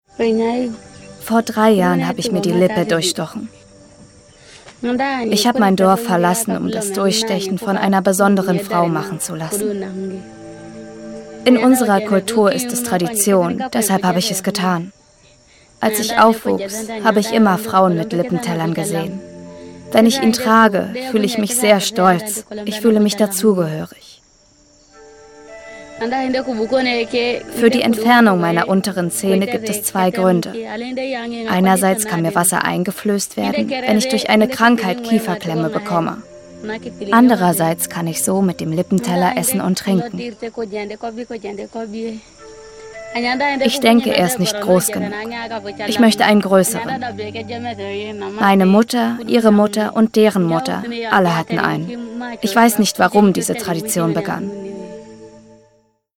Sychronausschnitt